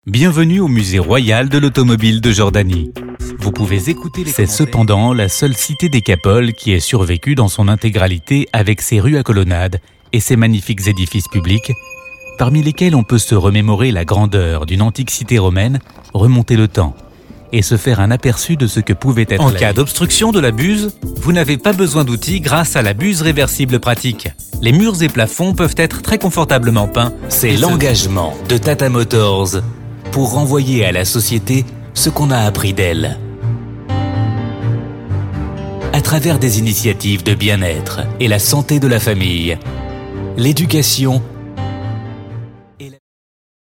FR EU JP DOC 01 Documentaries Male French (European)